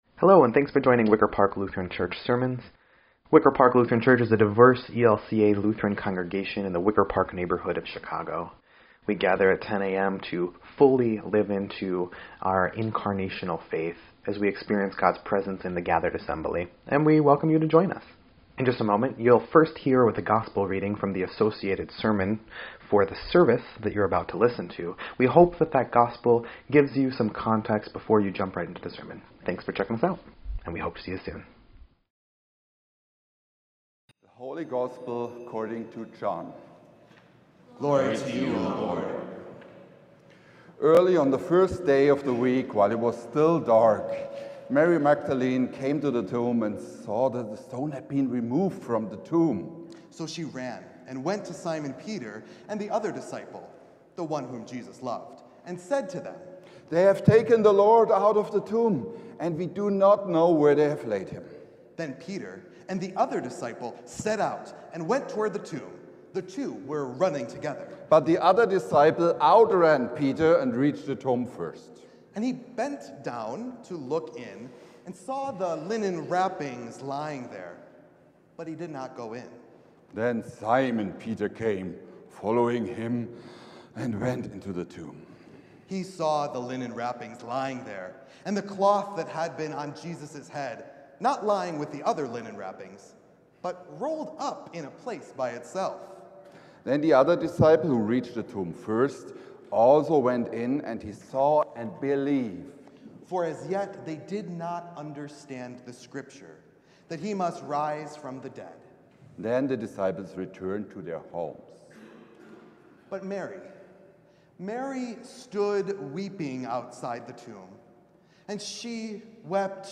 The Easter Vigil
4.19.25-Sermon_EDIT.mp3